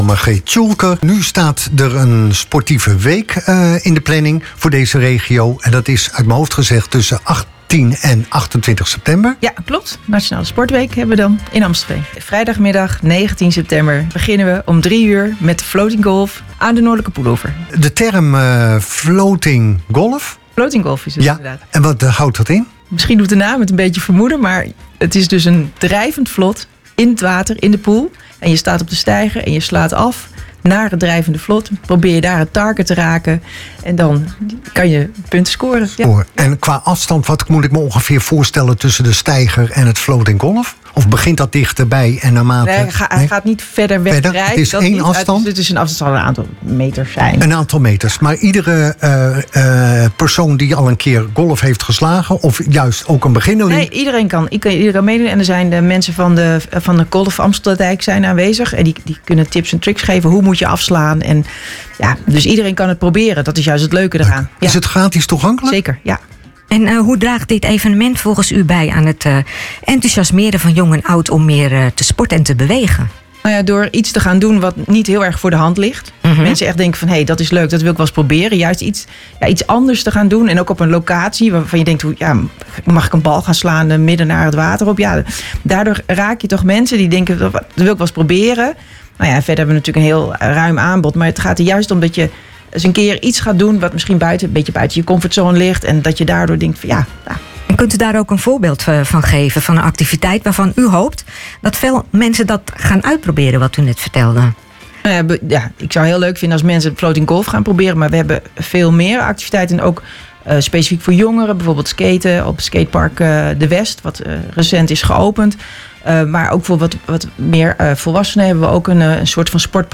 Tijdens het interview bij Jammfm in Start Me Up